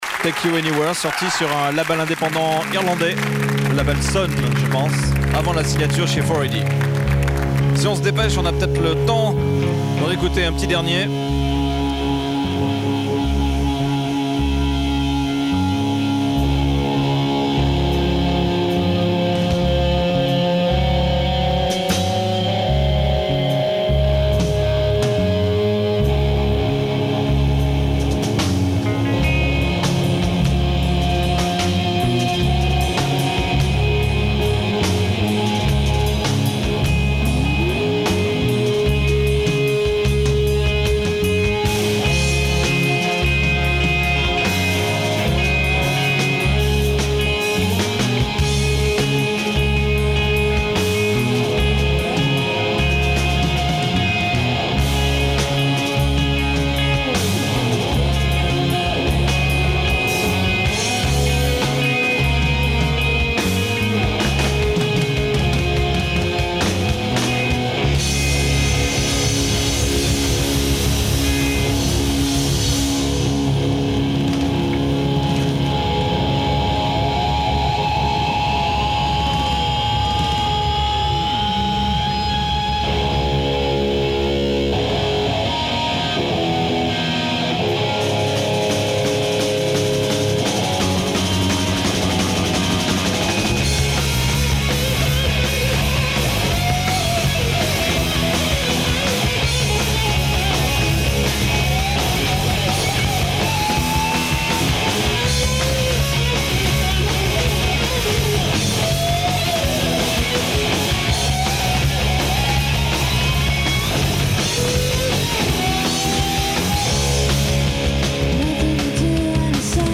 enregistrée le 28/05/1996  au Studio 105